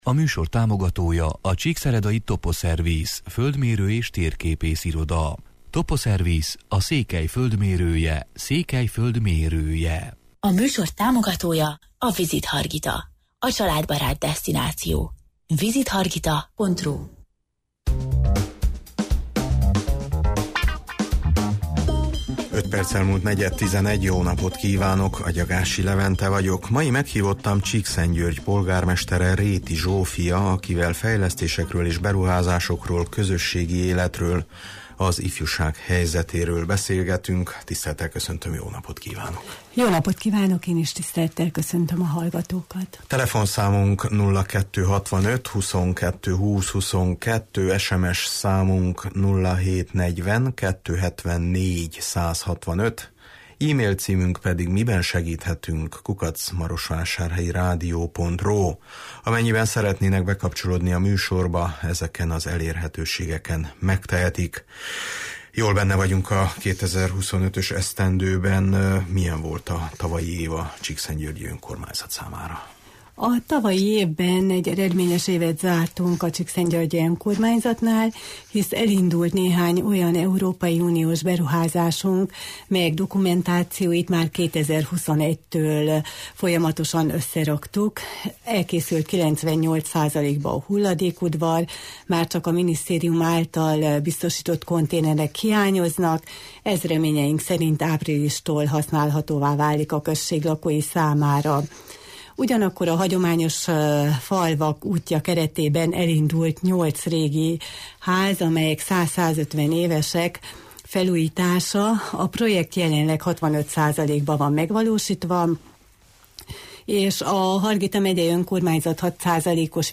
Mai meghívottam Csíkszentgyörgy polgármestere, Réti Zsófia, akivel fejlesztésekről és beruházásokról, közösségi életről, az ifjúság helyzetéről beszélgetünk: